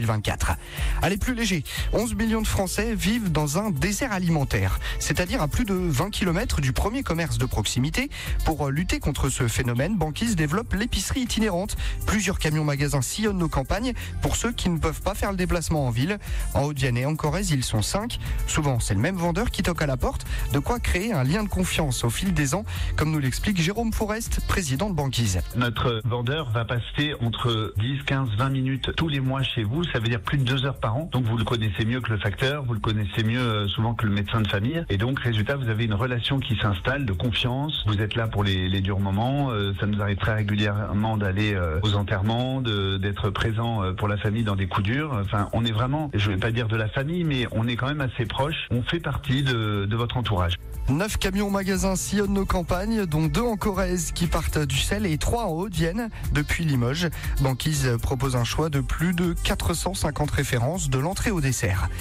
Notre entreprise a fait l’objet d’un sujet diffusé dans trois flashs info à 7h30, 8h00 et 8h30, mettant en lumière notre modèle d’épicerie rurale itinérante et notre rôle dans la lutte contre les déserts alimentaires.